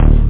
Amiga 8-bit Sampled Voice
303-analog.mp3